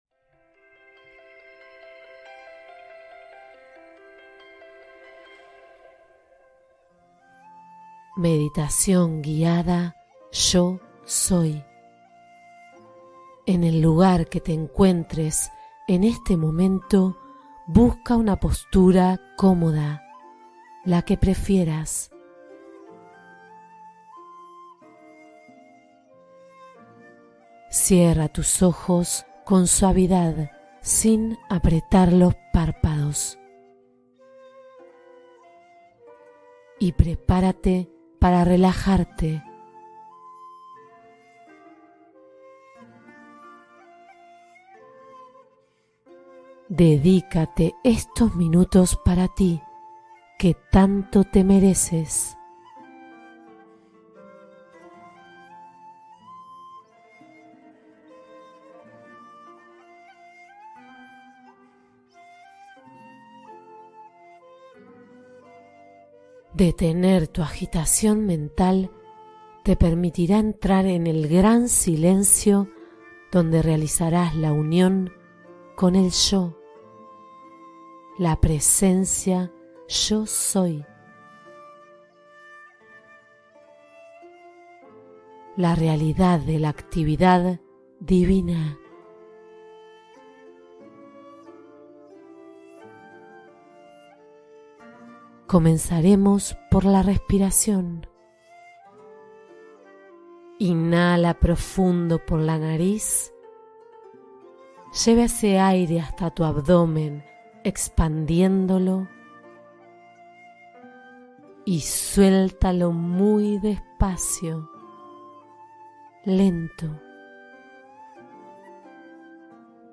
Conecta con tu poder interno con la meditación guiada “Yo soy”